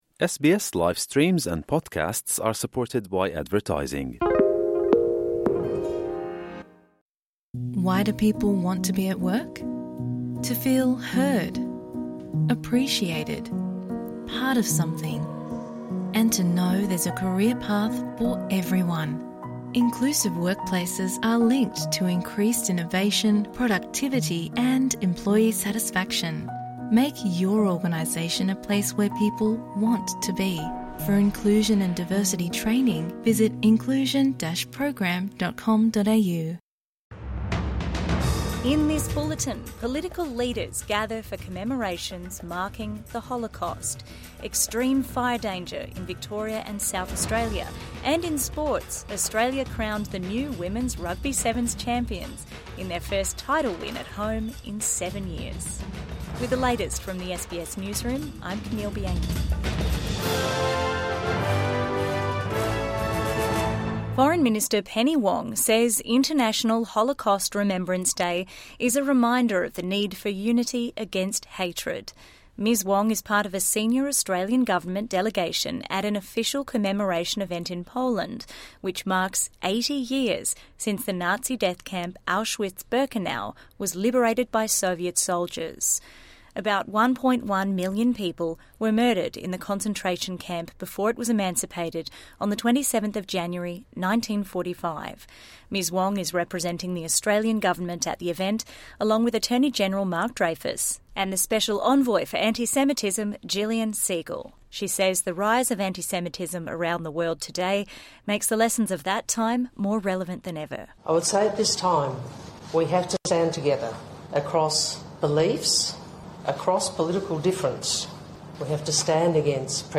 A bulletin of the day’s top stories from SBS News. Get a quick rundown of the latest headlines from Australia and the world, with fresh updates each morning, lunchtime and evening.